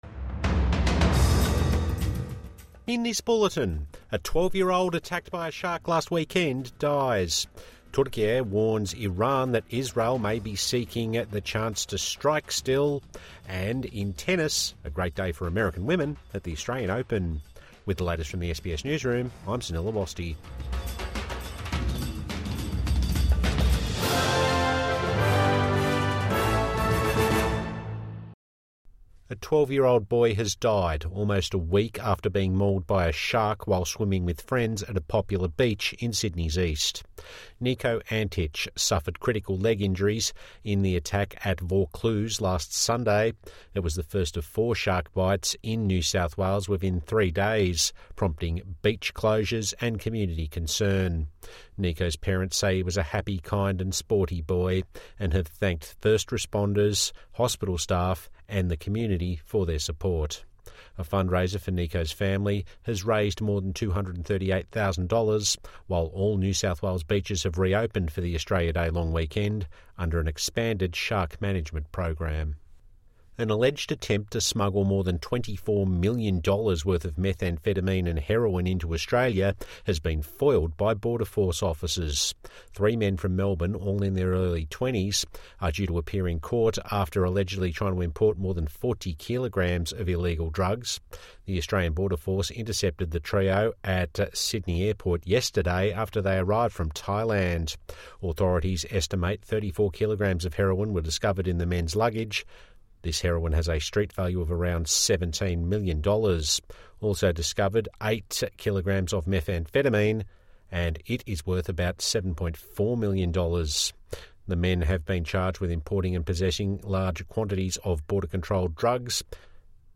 12-year-old mauled by shark last weekend dies | Evening News Bulletin 24 January 2026